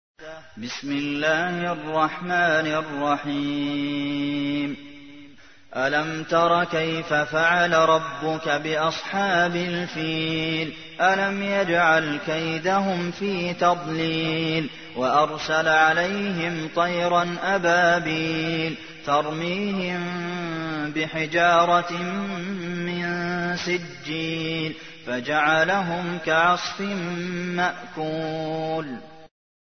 تحميل : 105. سورة الفيل / القارئ عبد المحسن قاسم / القرآن الكريم / موقع يا حسين